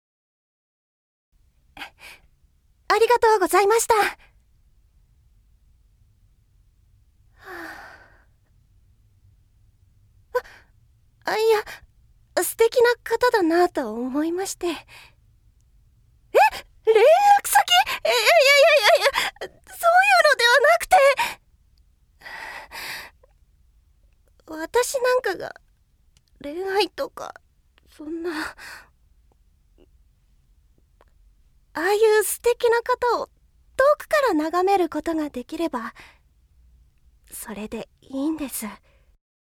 ◆気弱な女子高生◆